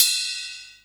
44 CYMB 1 -R.wav